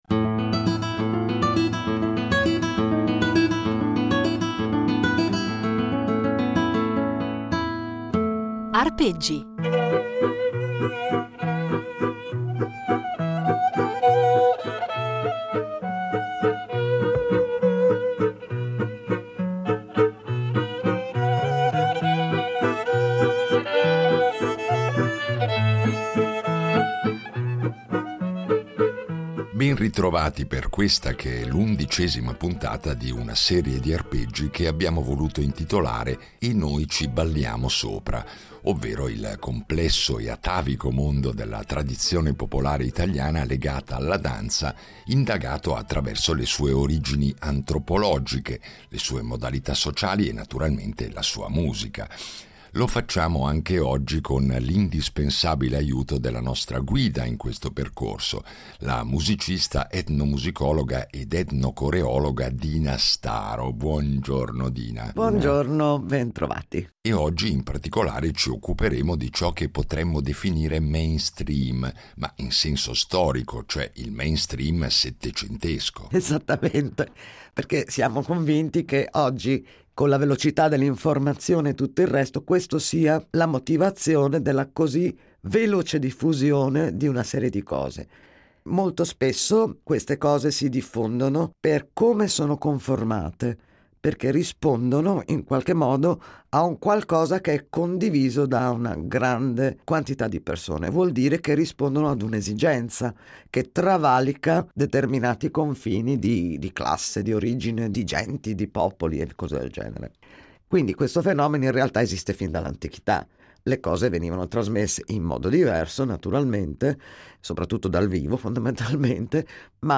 Un itinerario sonoro ricco di materiale inedito, registrato sul campo e negli anni da lei stessa